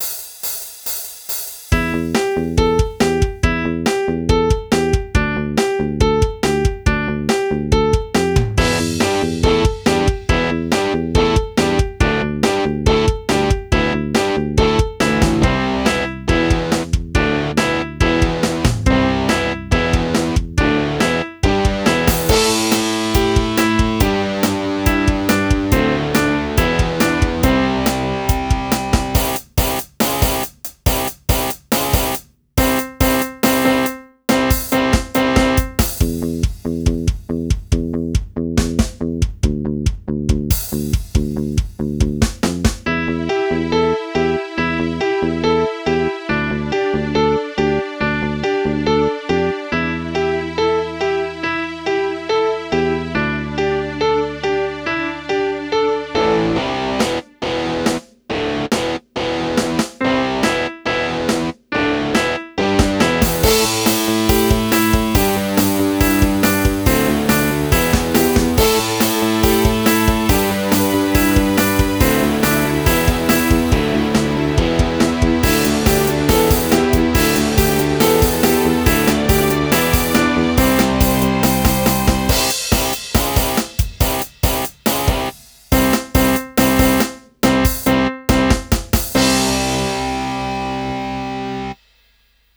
Palavras-chave: Jogos educacionais; Trilha original